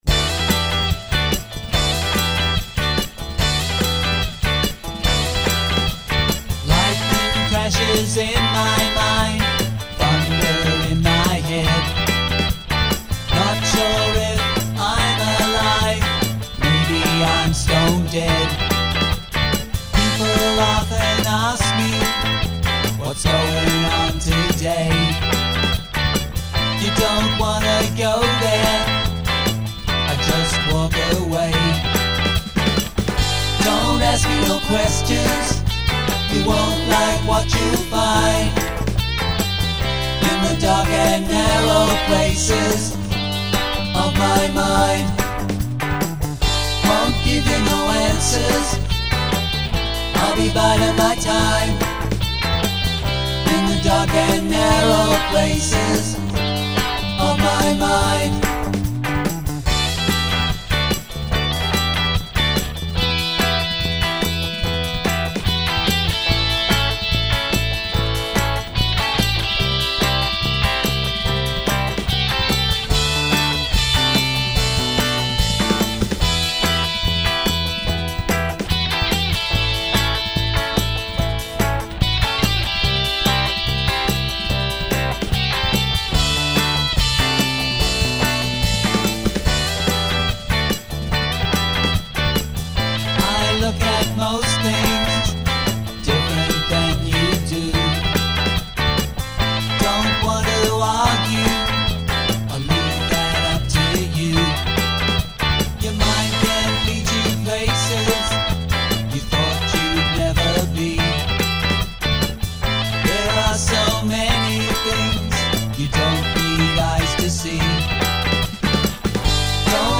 retro 60s garage pop
containing 14 original handcrafted catchy retro pop tunes .